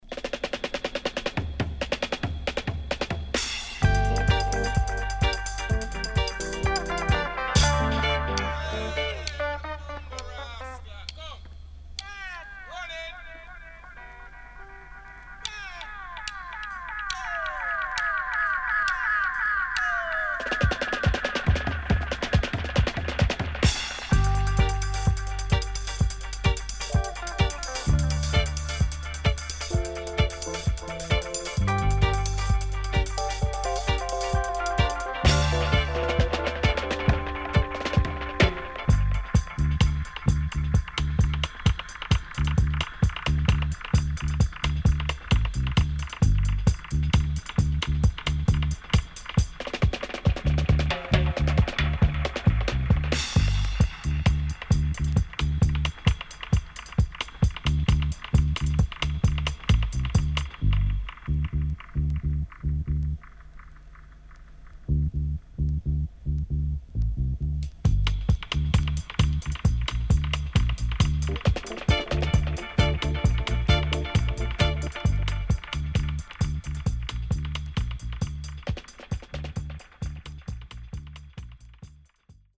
【12inch】
SIDE A:うすいこまかい傷ありますがノイズあまり目立ちません。